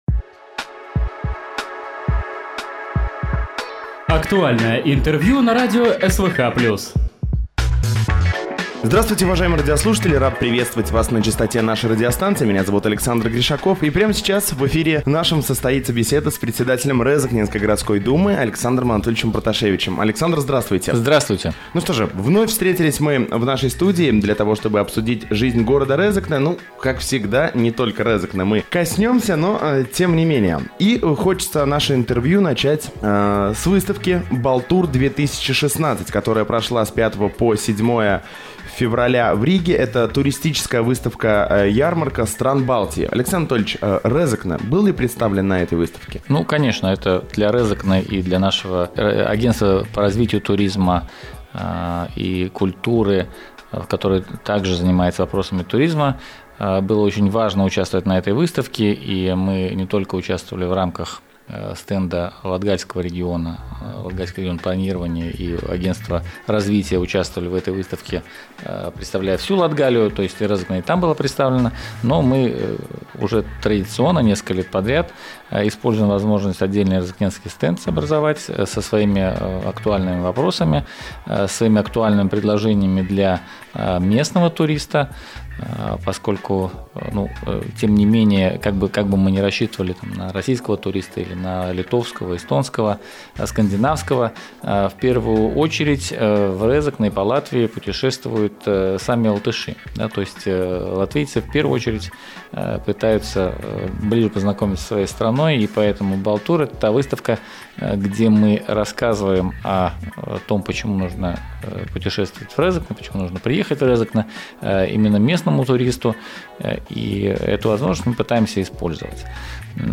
Гость в студии (10.02.16)